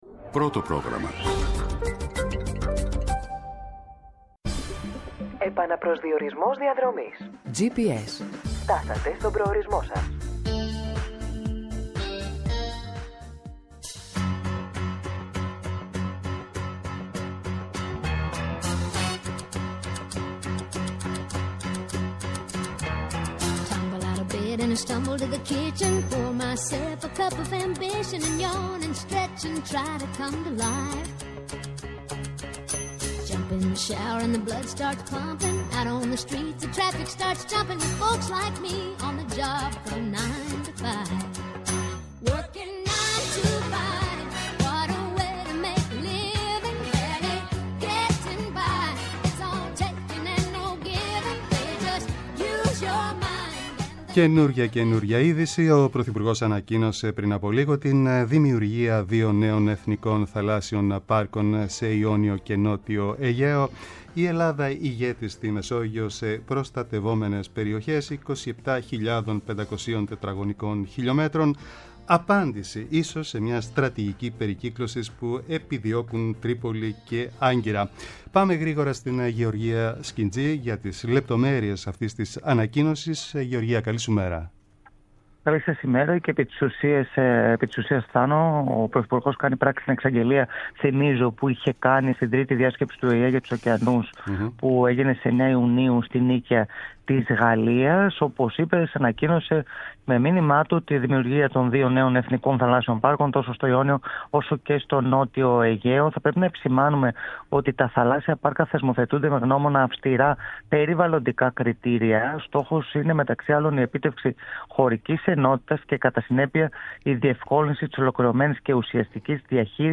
-Ο Θεόδωρος Κολυδάς, πρώην Διευθυντής Εθνικής Μετεωρολογικής Υπηρεσίας για την εξέλιξη του ισχυρού καύσωνα από σήμερα στη χώρα
-Ο Στέφανος Γκίκας, Υφυπουργός Ναυτιλίας για την επιβολή τέλους στην κρουαζιέρα